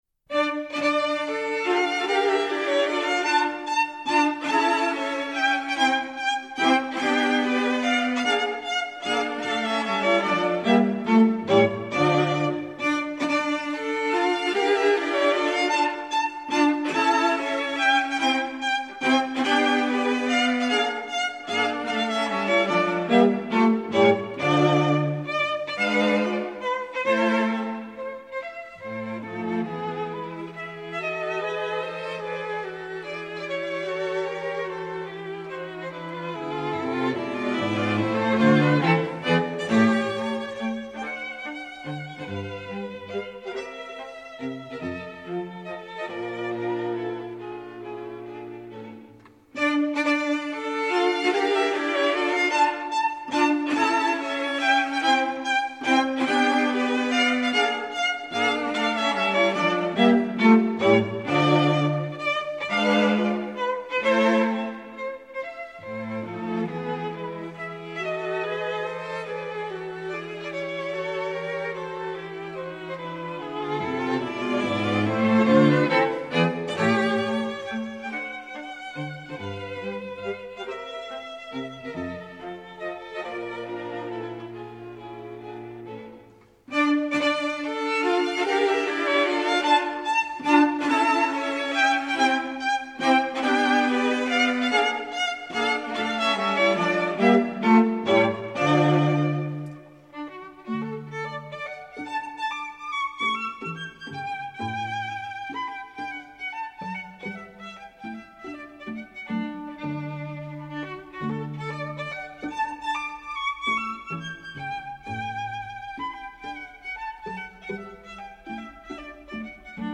String Quartet in D minor
Menuetto (Allegretto)